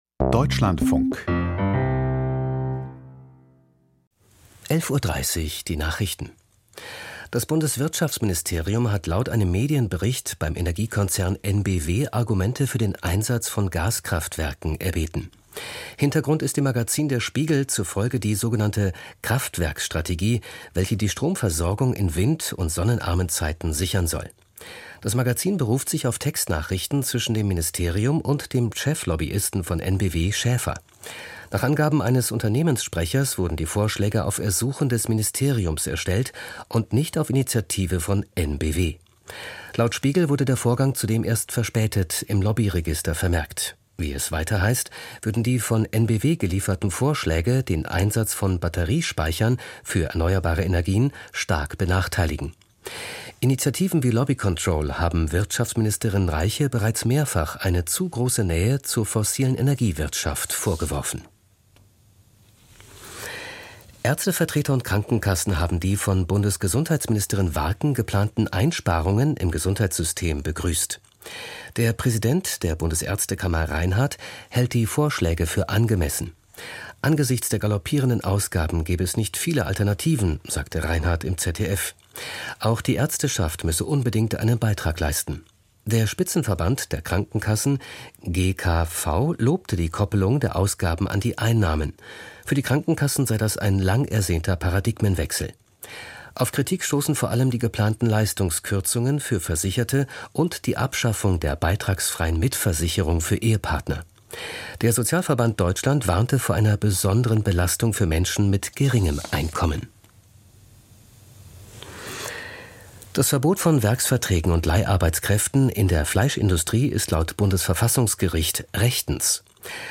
Die Nachrichten vom 15.04.2026, 11:30 Uhr